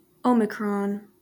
Omicron (US: /ˈmɪkrɒn, ˈɒmɪkrɒn/